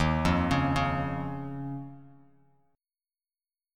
Eb7sus2 chord